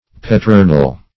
Petronel \Pet`ro*nel\, n.